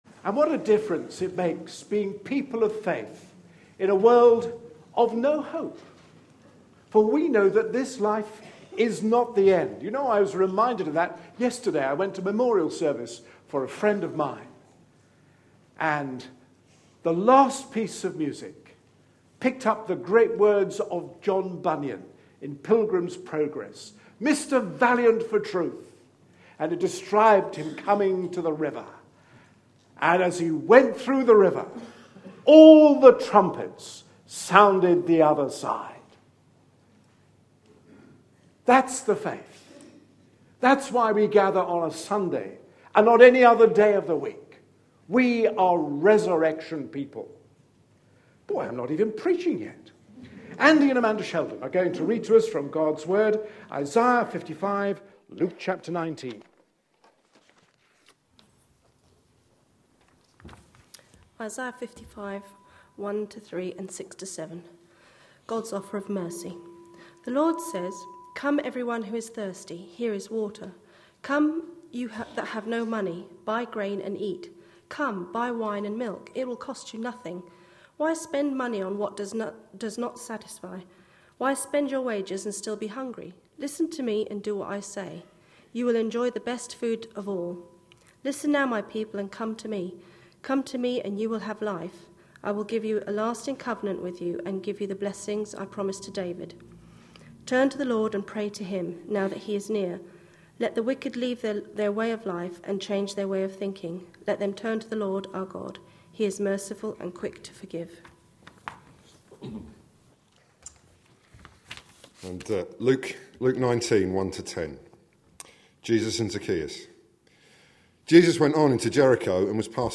A sermon preached on 9th February, 2014, as part of our Jesus -- His Challenge To Us Today. series.